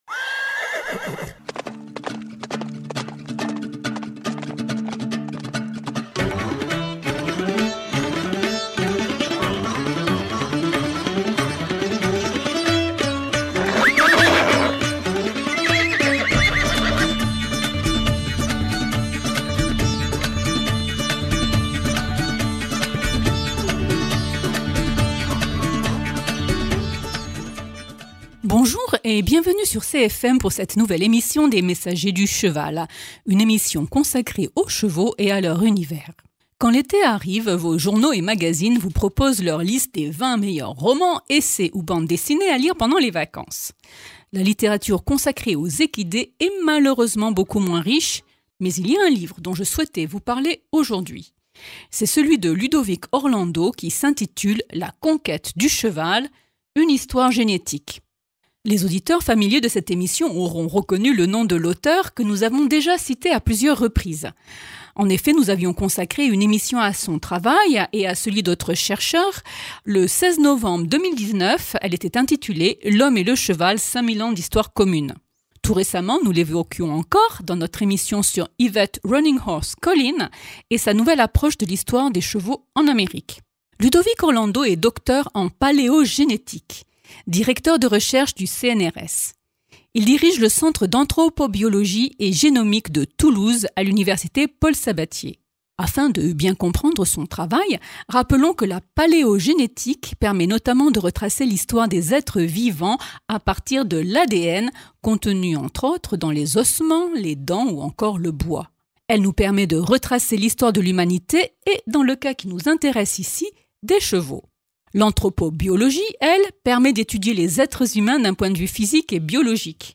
Un chercheur du CNRS de Toulouse nous fait part de ses dernières découvertes et des dernières découvertes sur l’histoire et l’origine du cheval dans un ouvrage qui vient de paraître.